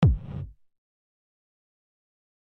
error.mp3